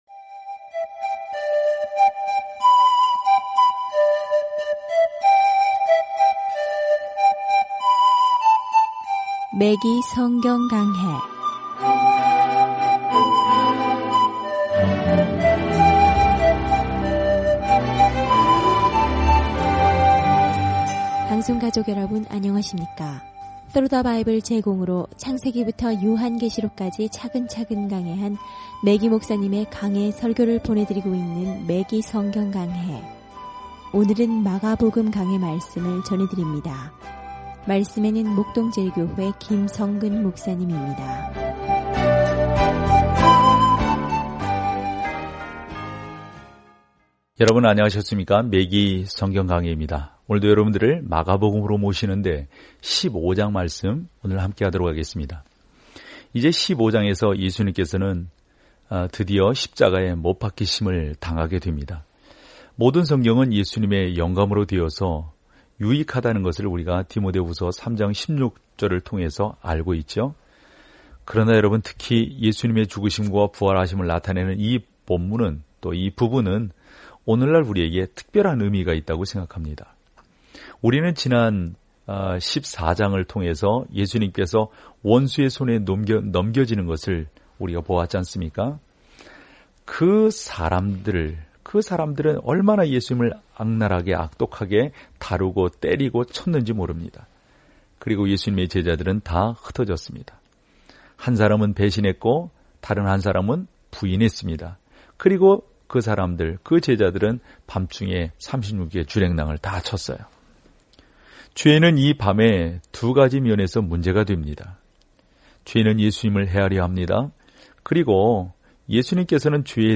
말씀 마가복음 15:1-26 37 묵상 계획 시작 39 묵상 소개 마가복음의 짧은 복음서는 예수 그리스도의 지상 사역을 고통받는 종이자 사람의 아들로 묘사합니다. 오디오 공부를 듣고 하나님의 말씀에서 선택한 구절을 읽으면서 매일 마가복음을 여행하세요.